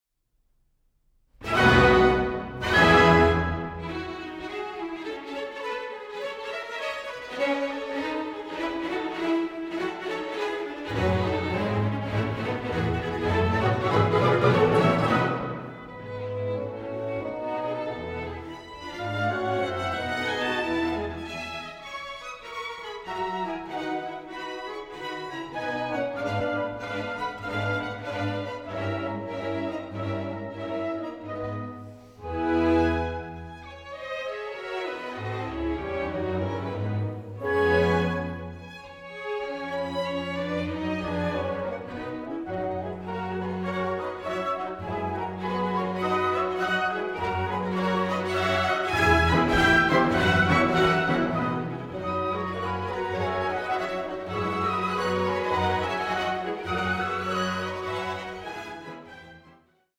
sparkling dialogue between strings and winds